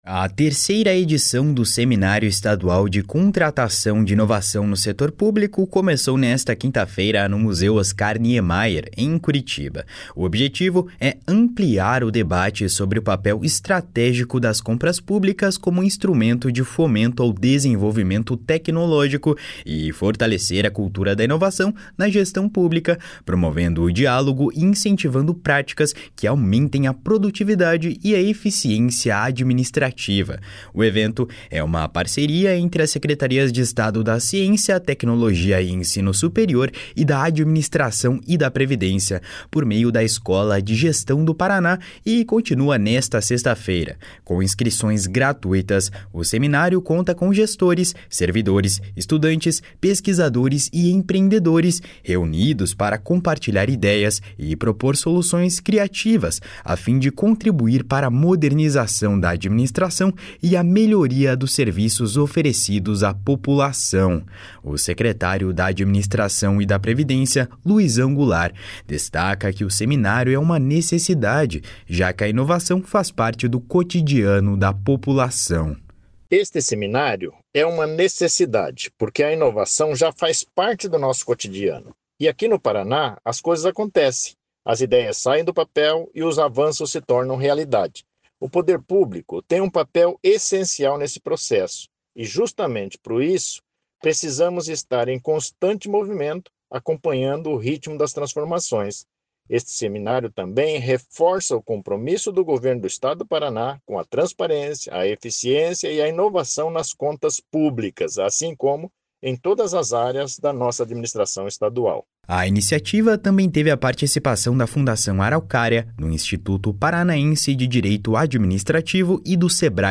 O secretário da Administração e da Previdência, Luizão Goulart, destaca que o seminário é uma necessidade, já que a inovação faz parte do cotidiano da população. // SONORA LUIZÃO GOULART //